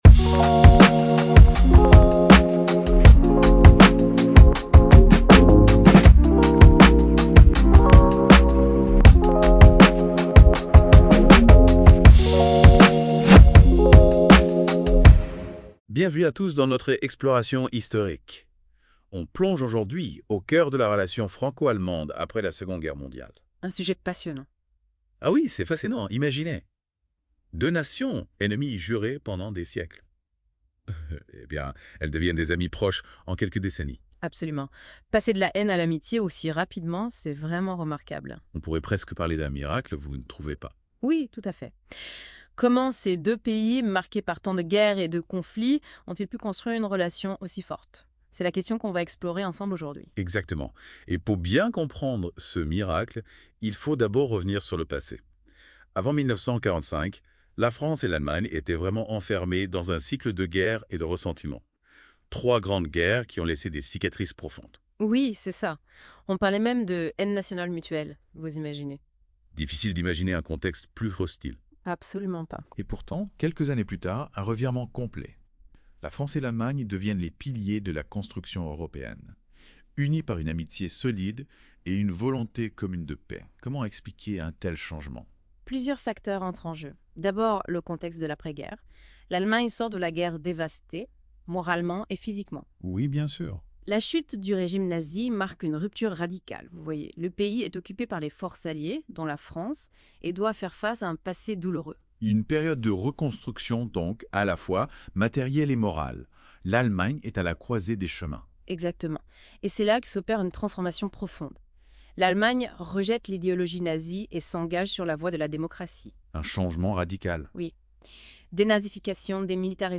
Notebook LM (IA)